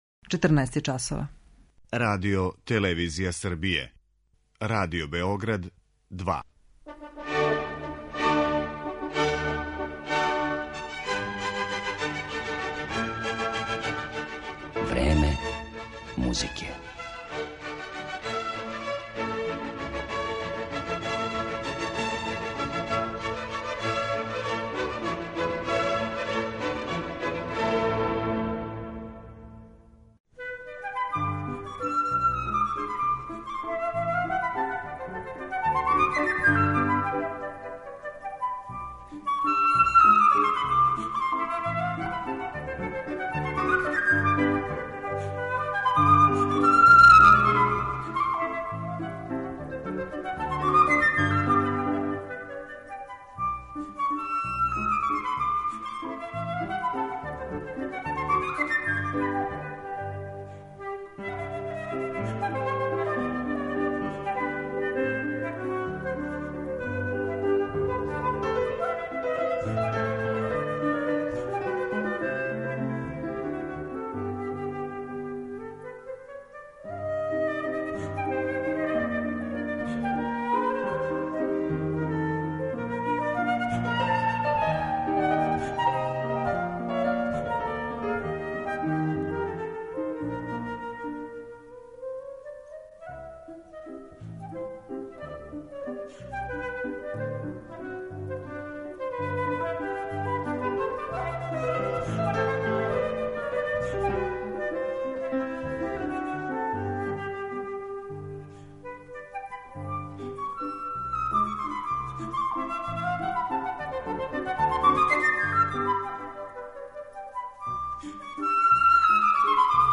Познатом француском флаутисти Патрику Галуа, посвећена је данашња емисија Време музике, у којој ће моћи да се чује и интервју са овим славним солистом снимљен приликом једног од његових гостовања у Београду.
Поред композиција својих сународника, Мориса Равела и Франсиса Пуленка, Патрик Галуа ће изводити и дела Хоакина Родрига и Арама Хачатурјана.